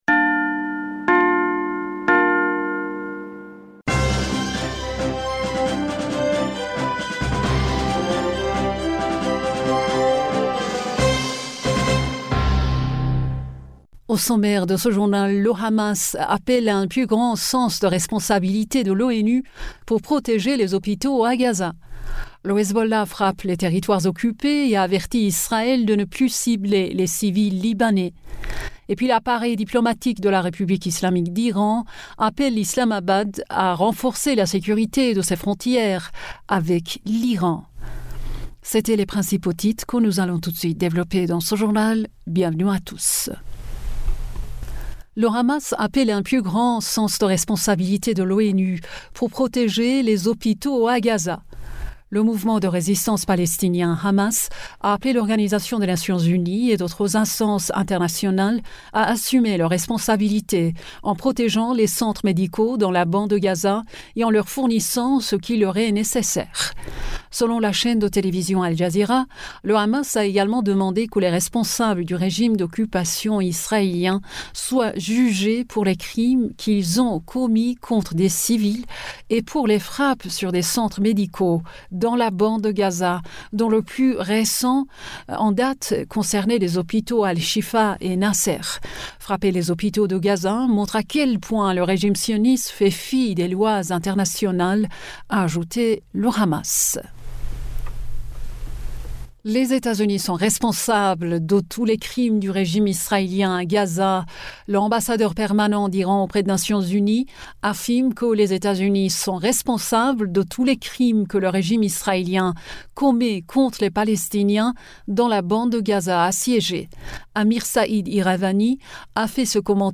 Bulletin d'information du 19 Decembre 2023